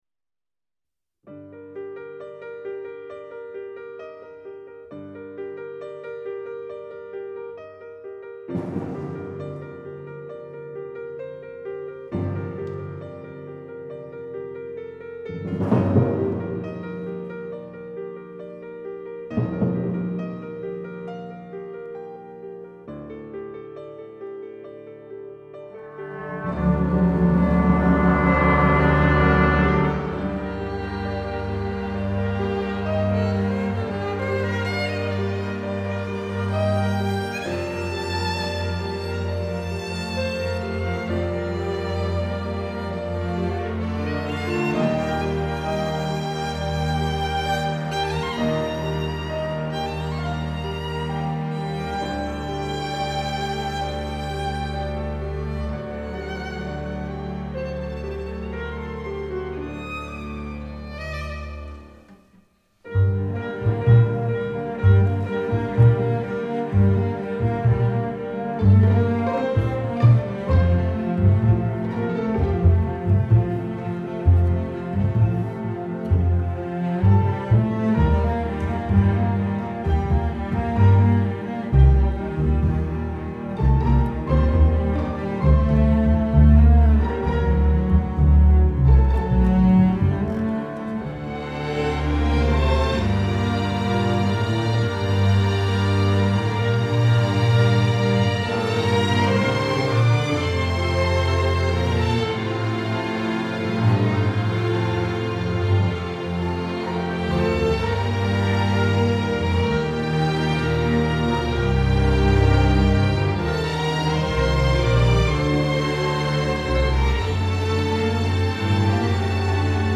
Ορχηστρική μουσική